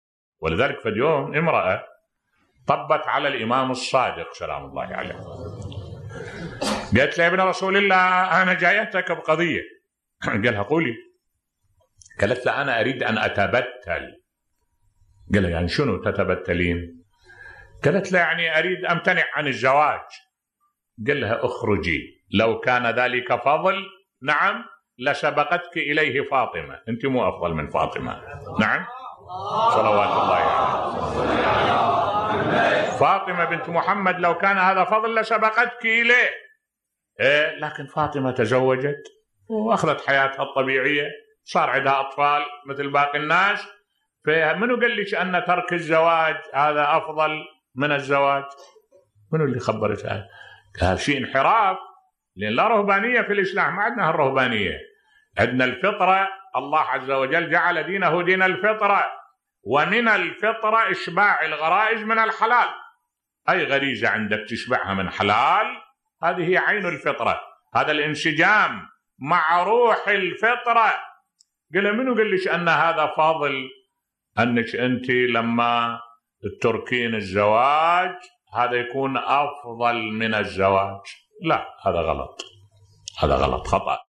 ملف صوتی امرأة تسأل الإمام الصادق(ع) تقول له أريد امتنع عن الزواج بصوت الشيخ الدكتور أحمد الوائلي